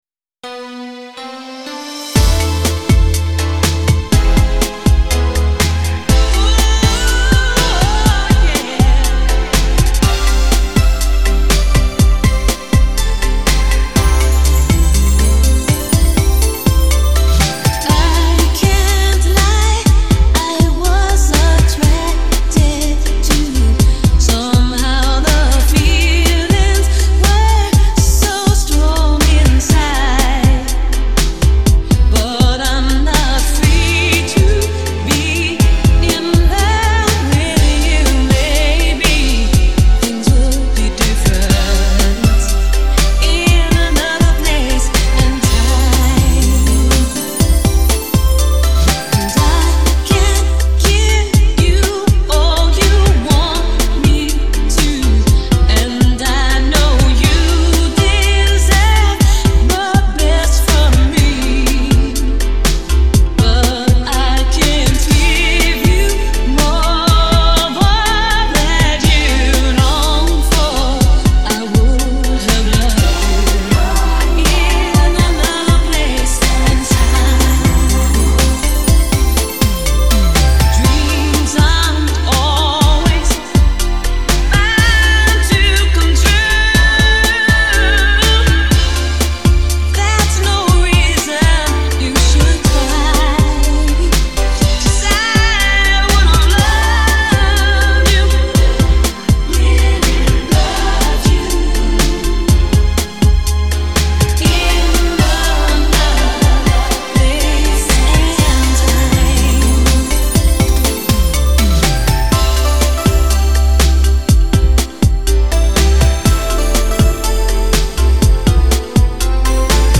Genre : Disco